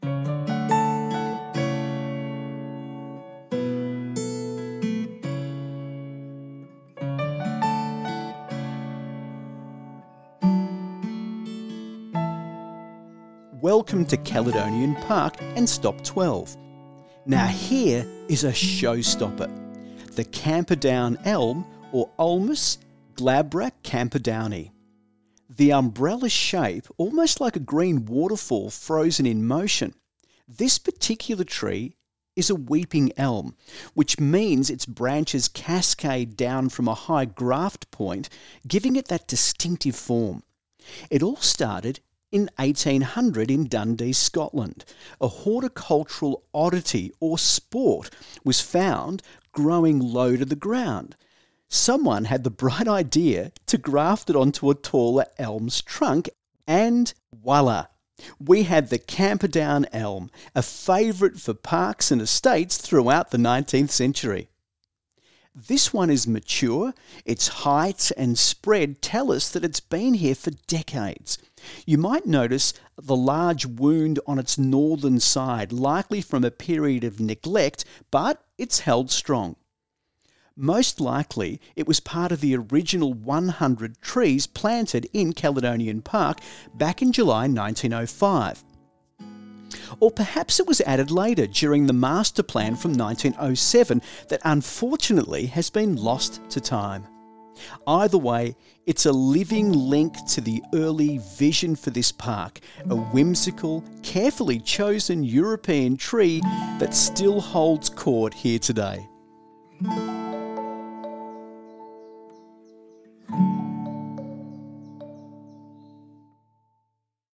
Audio Tour of the Ballan Historic Tree Walk